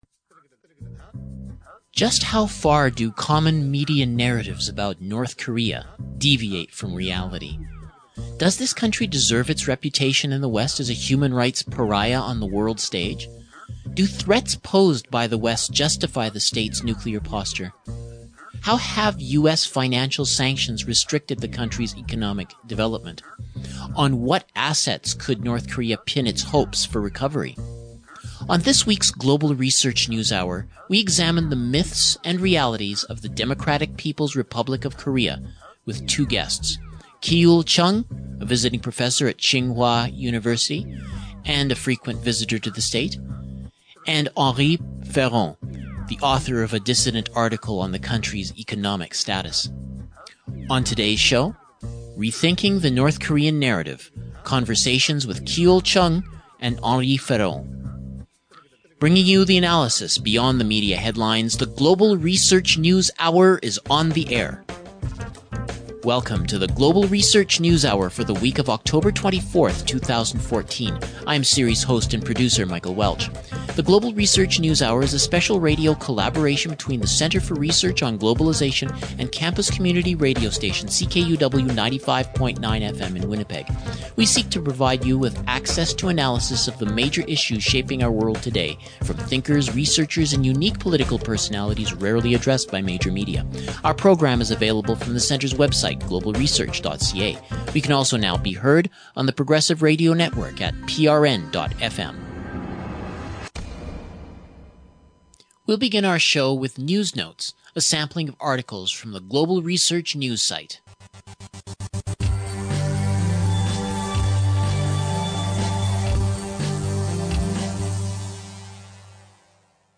Rethinking the North Korean Narrative: Conversations